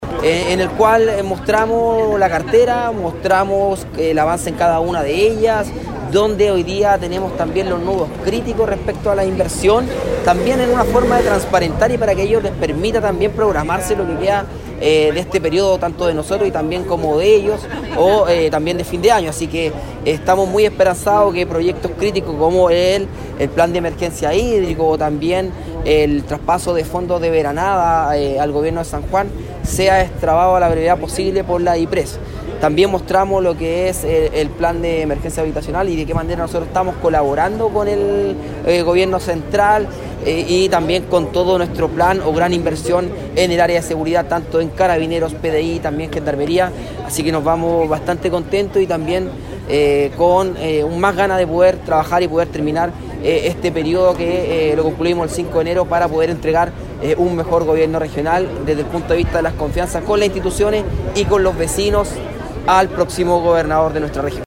En este encuentro, en el cual asistieron jefes comunales, consejeros regionales y el delegado presidencial regional Galo Luna, el gobernador abordó las novedades en distintas áreas, tanto en agricultura, emergencia habitacional, programas de seguridad y empleo en todas las comunas de la región, así lo comentó el gobernador regional Darwin Ibacache.